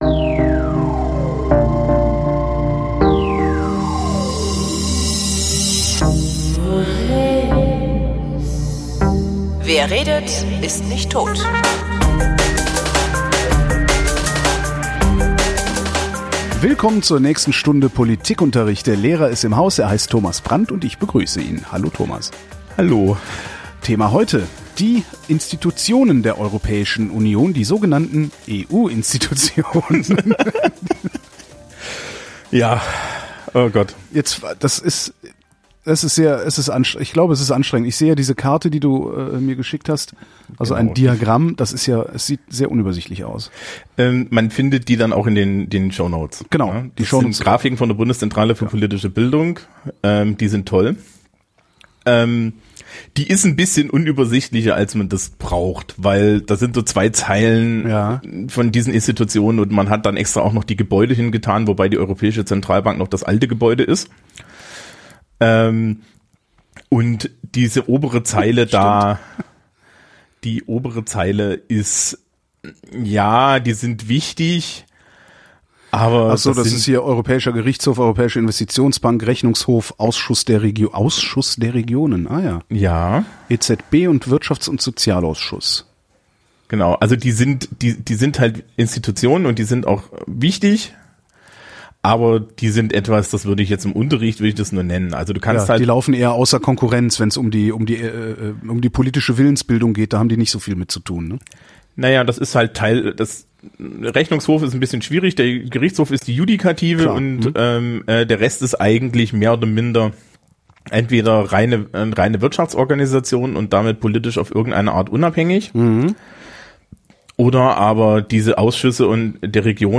Weil in jeder Sendung geredet wird, habe ich mir erlaubt, das Projekt nach einem Satz aus Gottfried Benns Gedicht “Kommt” zu benennen.